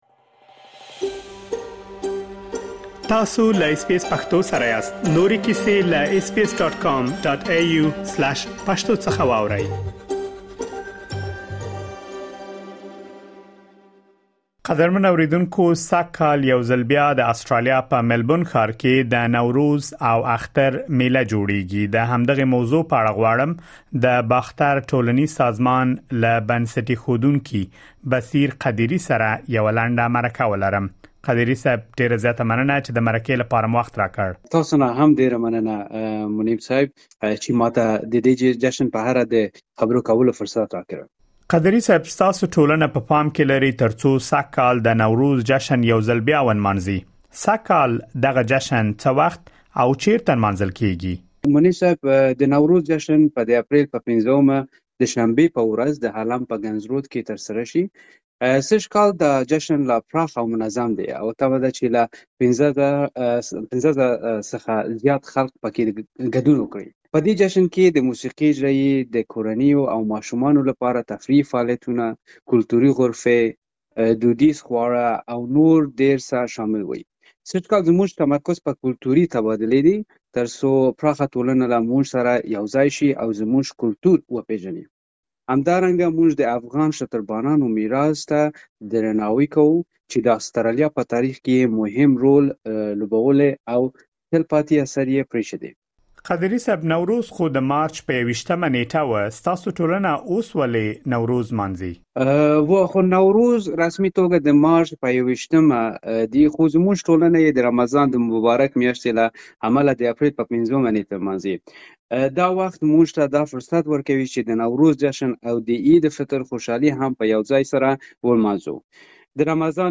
تاسو کولی شئ لا ډېر معلومات په ترسره شوې مرکې کې واورئ.